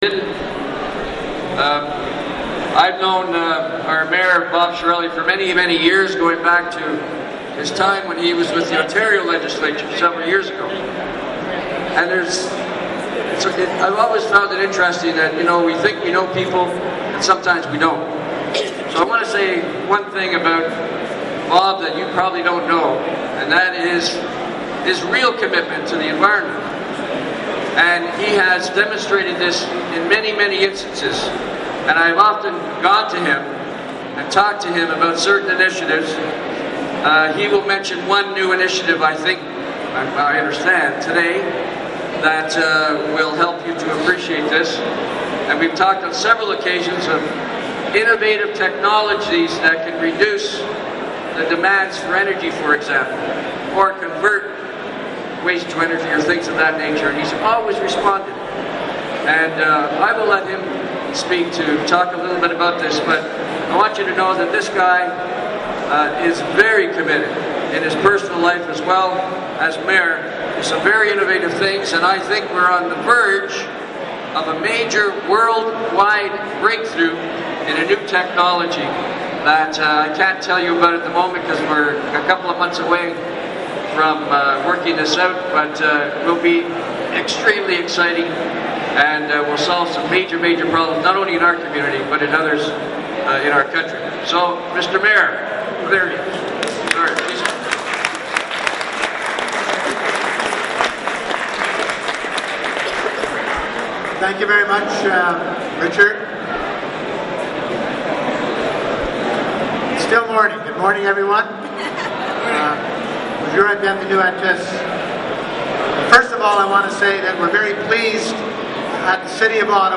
MAYOR SPEECH AT ECO-FAIR
I attended the Eco Fair on Saturday, and was pleased to hear the mayor's pledge to the environment.
BobChiarelli_EarthDay_RichardPattenIntro.mp3